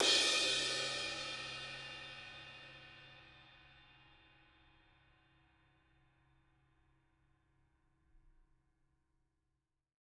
R_B Crash A 01 - Room.wav